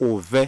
[-ATR]
Listen to Ega UvE 'dog' U ʻdogʼ
ega-dog.wav